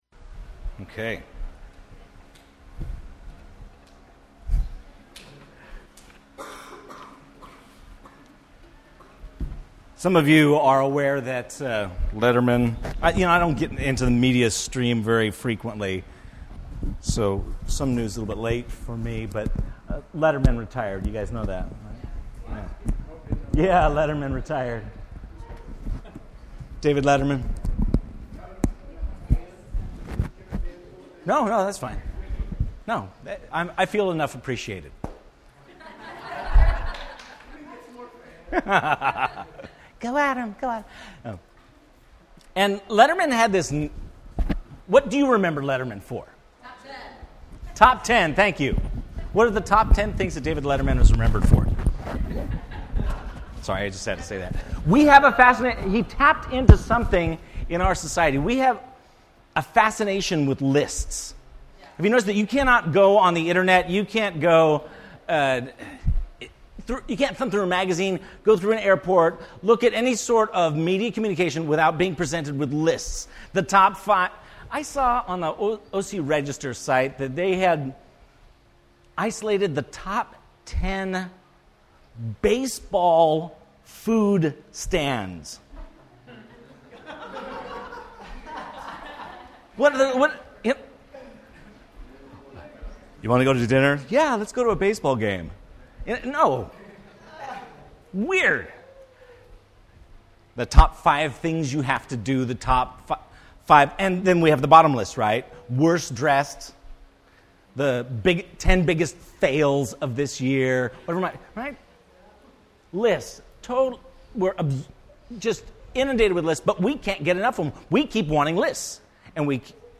Metaphors of the Church Service Type: Sunday Morning %todo_render% Related « Father Day 2015